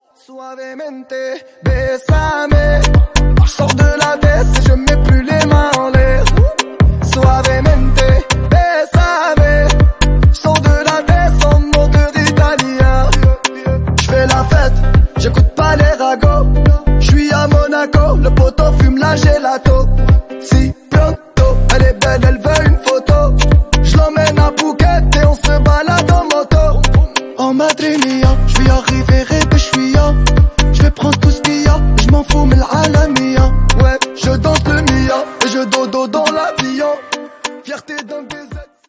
Pop ,Uncategorized